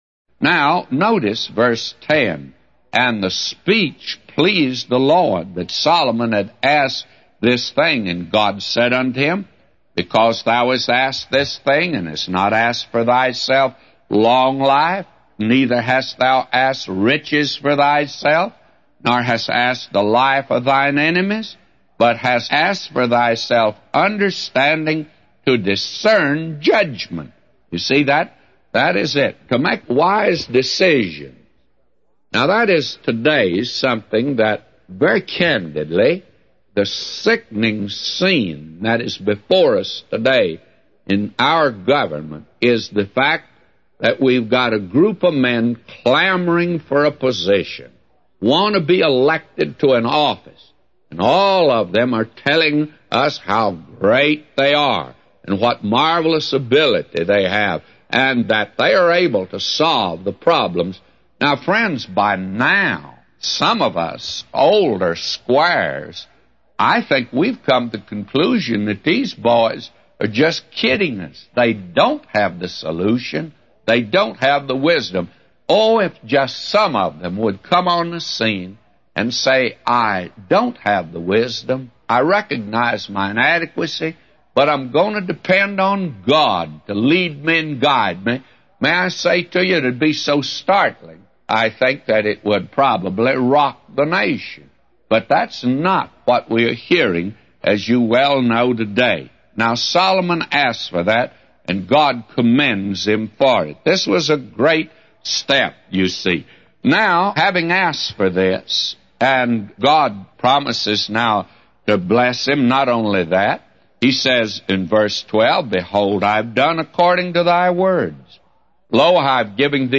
A Commentary By J Vernon MCgee For 1 Kings 3:10-999